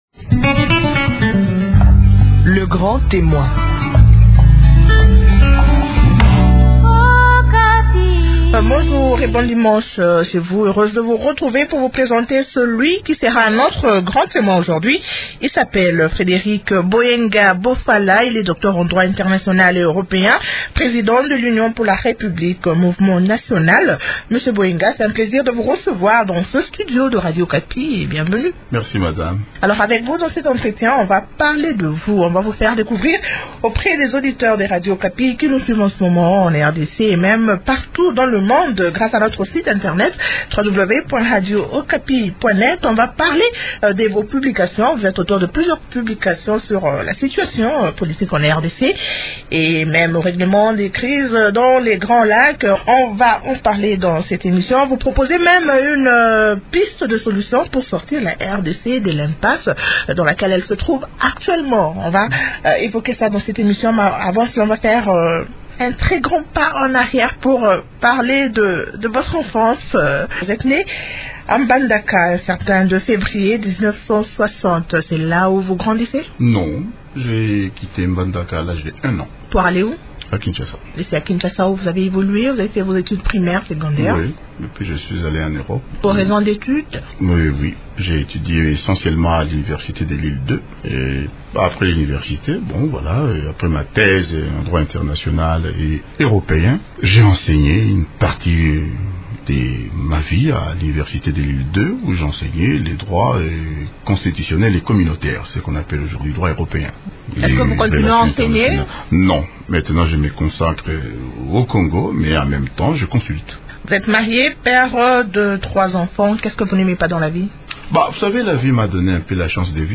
Le juriste appelle à la mise en place de la Geroncia, un conseil national des anciens pour la facilitation et la coordination d’un synode national. Pour lui, les Congolais sont les mieux placés pour régler leurs différends et pas les étrangers. Suivez l’entretien.